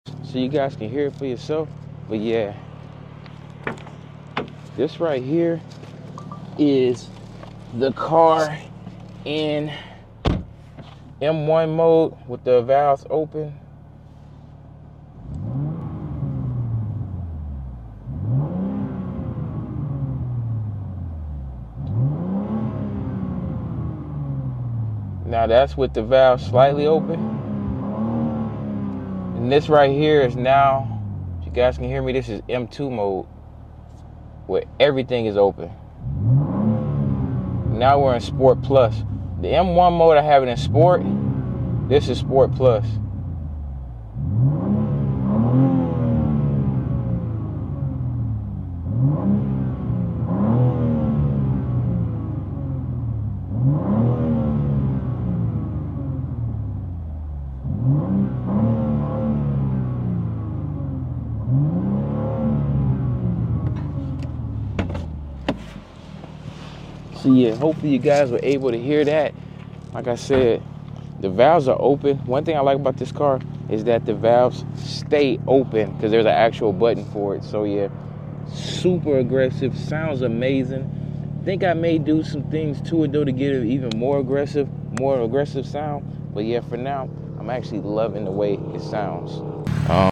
2024 BMW M2 stock exhaust sound effects free download
2024 BMW M2 stock exhaust sounds